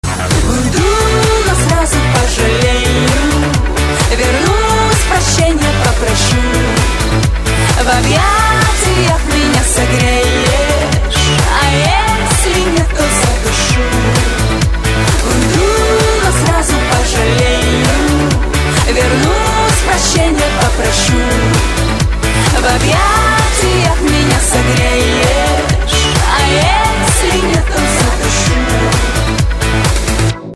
• Качество: 128, Stereo
поп
женский вокал
веселые
dance